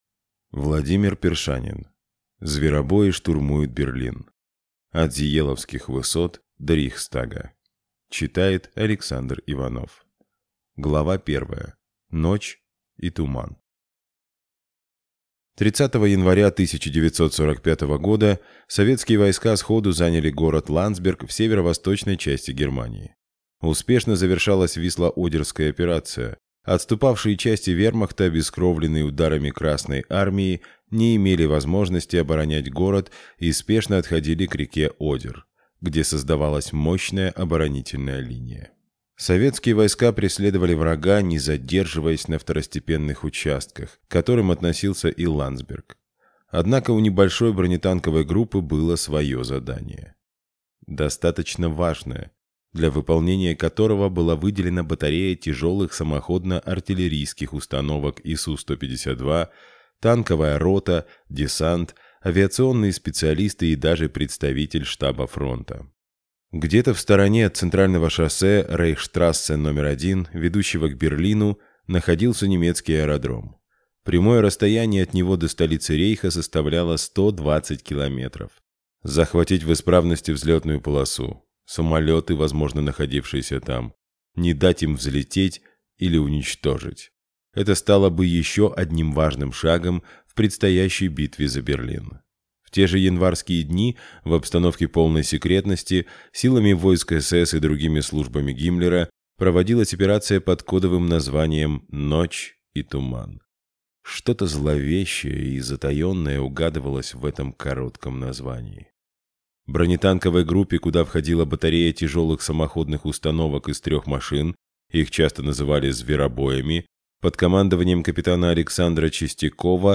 Аудиокнига «Зверобои» штурмуют Берлин. От Зееловских высот до Рейхстага | Библиотека аудиокниг